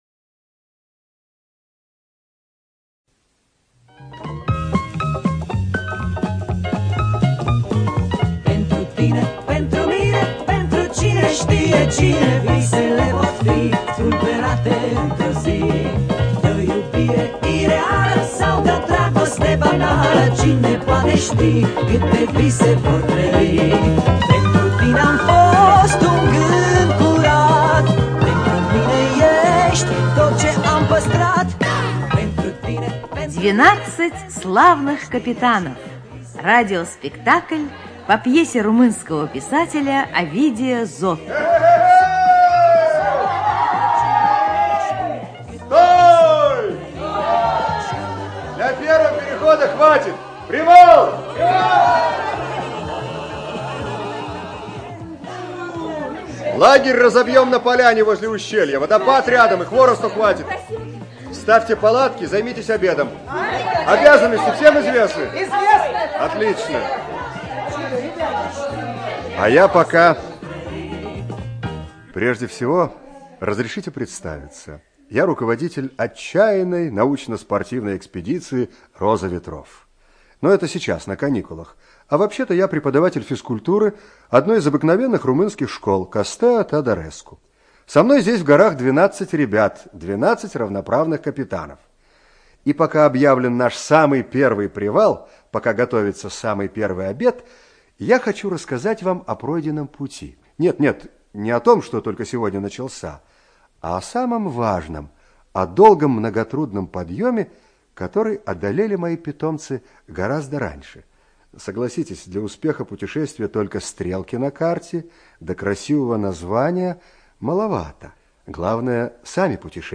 ЖанрДетский радиоспектакль